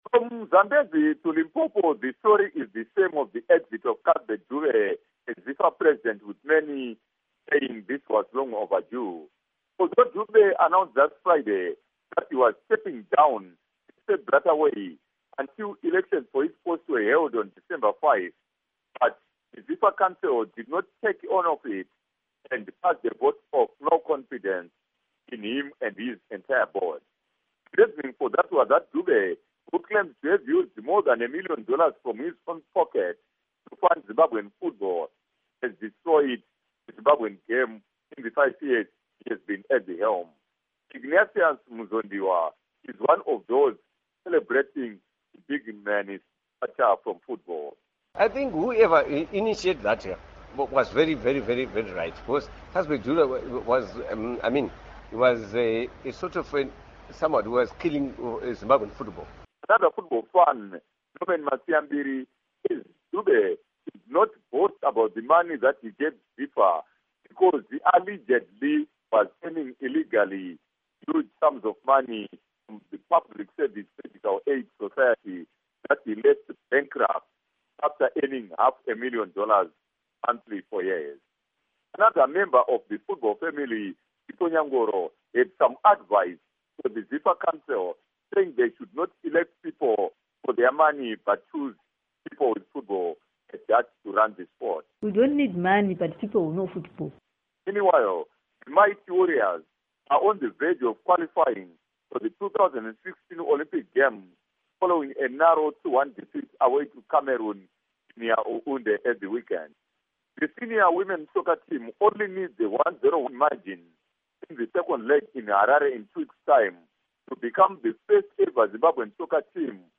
Report on ZIFA